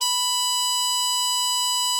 bari_sax_083.wav